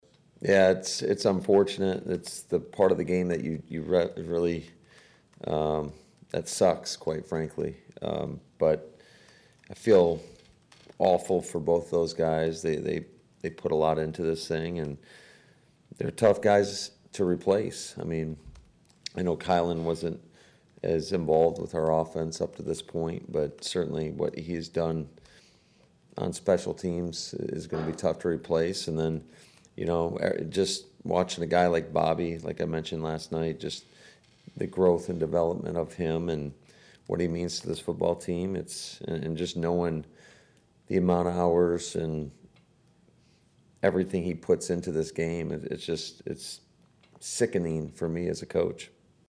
LaFleur met the media late Friday afternoon and talked about losing two more players including a key piece for the offense.